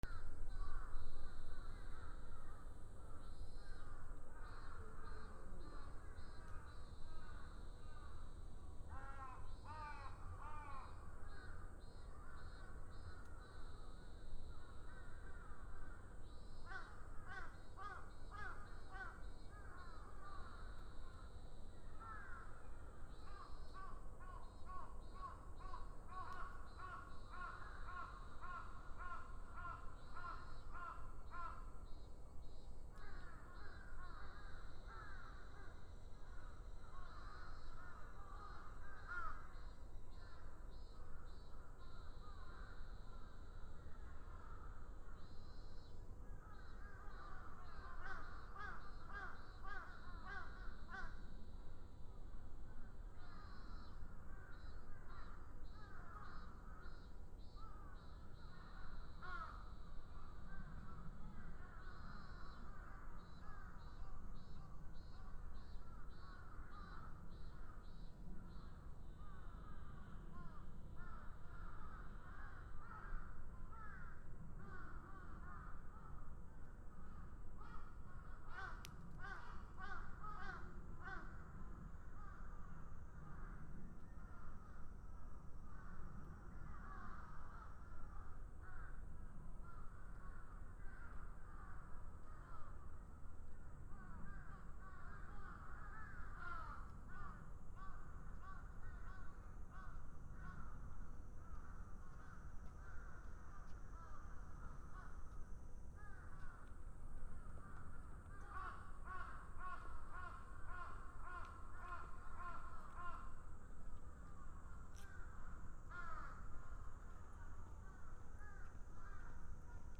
初夏 夕方 カラス セミ
/ B｜環境音(自然) / B-25 ｜セミの鳴き声 / セミの鳴き声_70_初夏